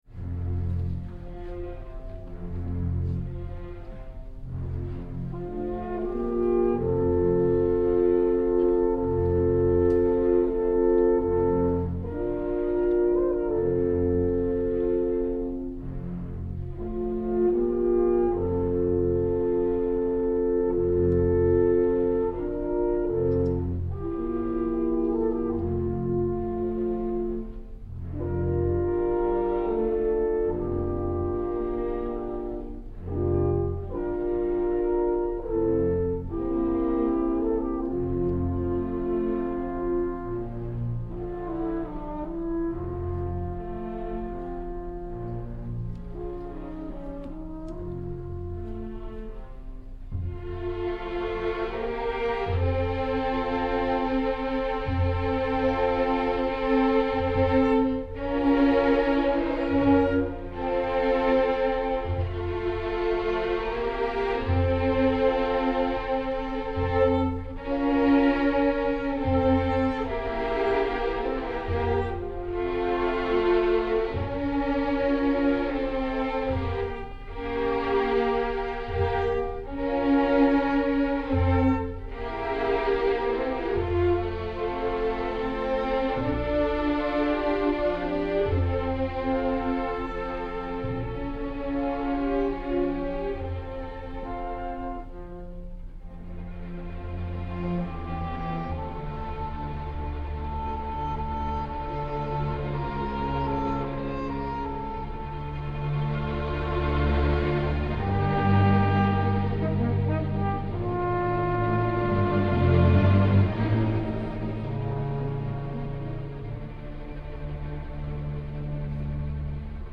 Notice that there are no pauses between the open and stopped passages – an excellent reason to work on a smooth and quick transition between open and stopped hand positions.  Here is a recording of that excerpt, recorded live from the Monroe Symphony’s concert on Saturday, May 7, 2011.
Overall I am happy with the performance, although the hall is very dry as you can tell from the recording, and the stopped horn passages don’t cut through as well as I would have liked.